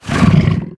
role3_pain1.wav